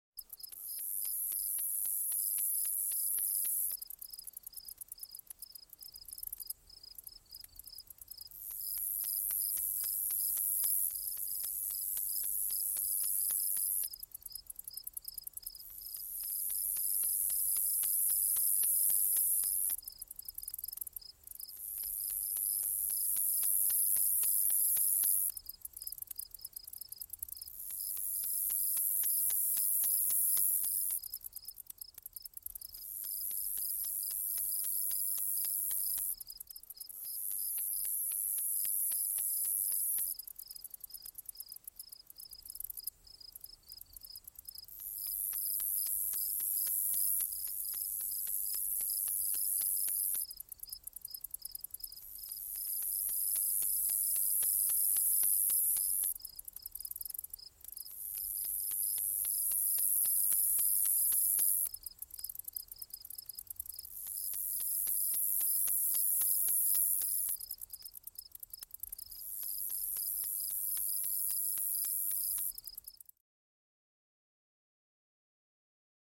دانلود صدای جیرجیرک 5 از ساعد نیوز با لینک مستقیم و کیفیت بالا
جلوه های صوتی
برچسب ها: دانلود آهنگ های افکت صوتی انسان و موجودات زنده دانلود آلبوم صدای پرندگان و حشرات از افکت صوتی انسان و موجودات زنده